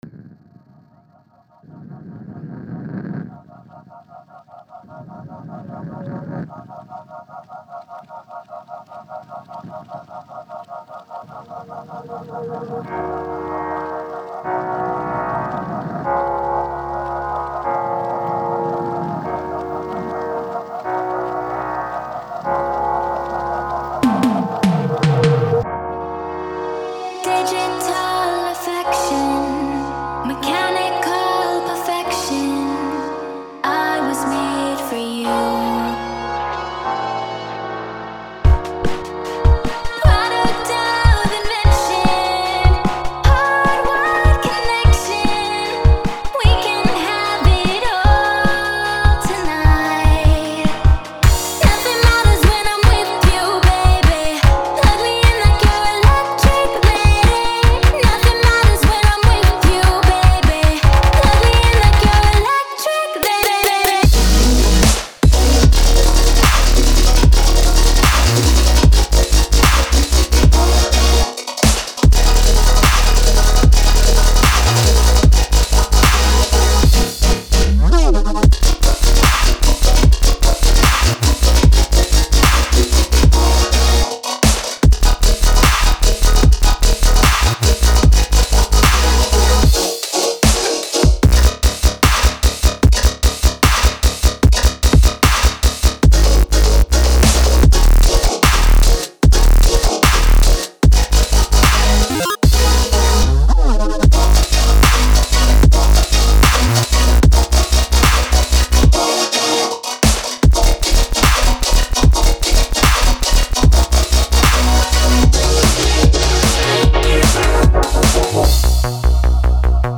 Submission Description: yes; another remix
dubstep